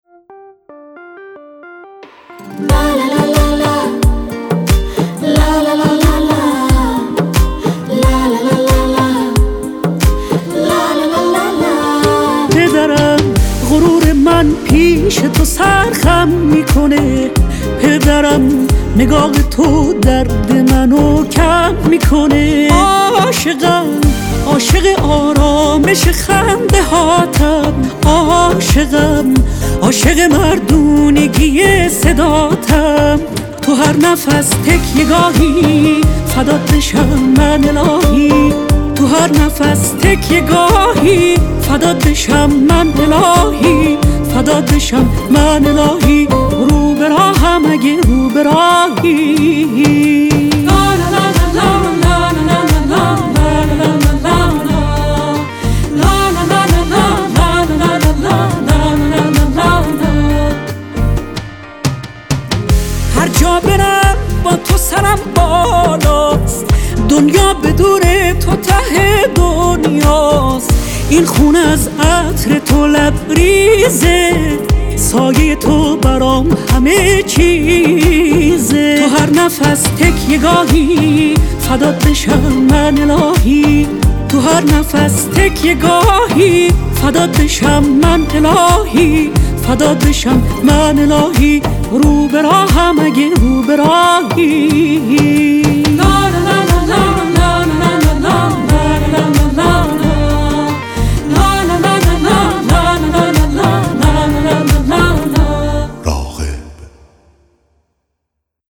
عالیه صداش یه آرامش خاصی داره